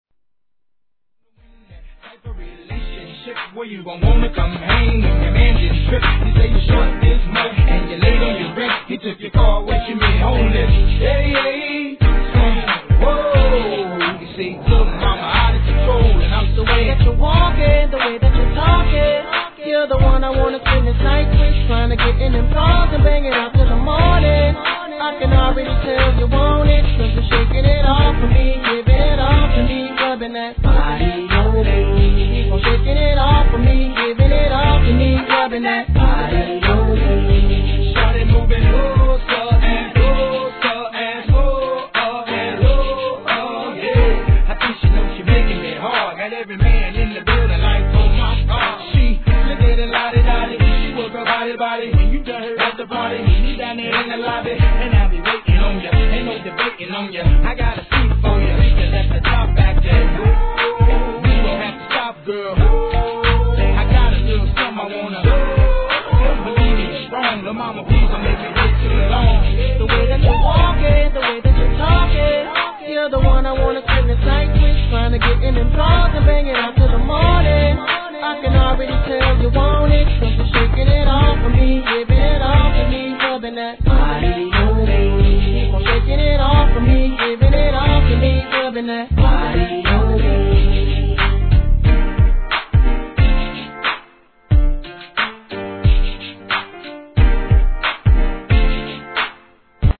HIP HOP/R&B
(BPM90)